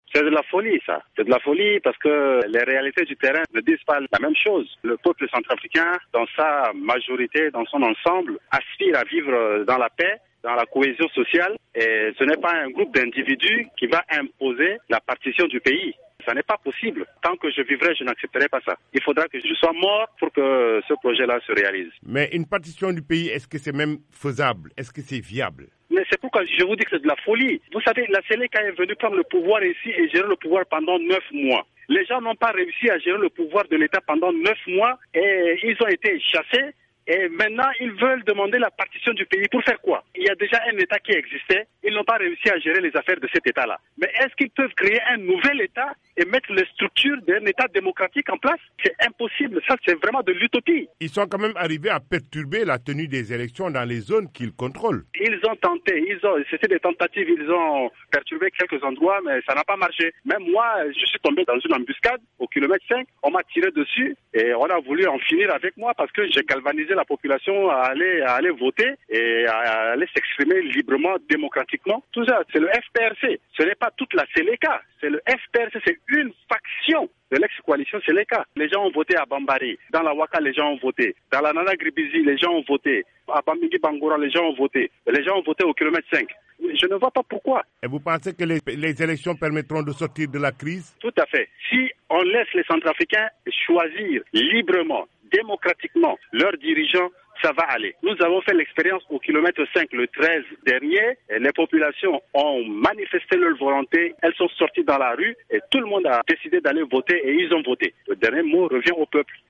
joint à Bangui